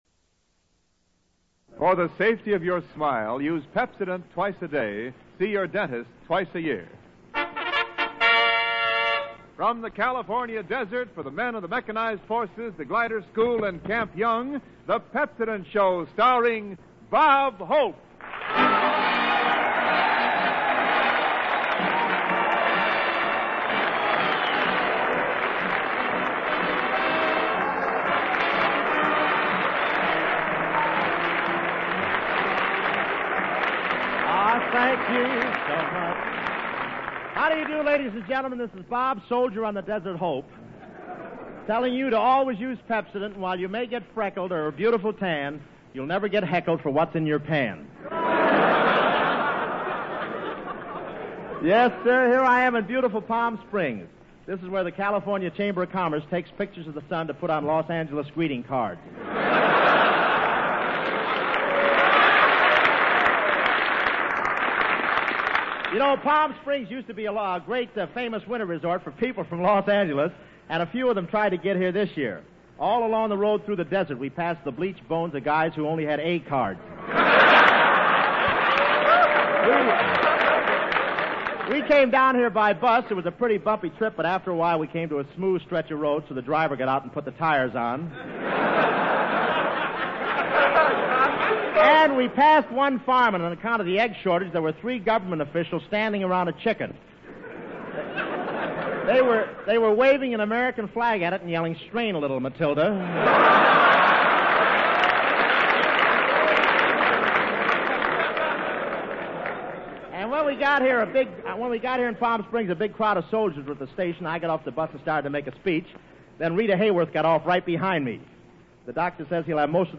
OTR Christmas Shows - From Camp Young, Palm Springs, California - Rita Hayworth - 1943-01-05 The Pepsodent Show Starring Bob Hope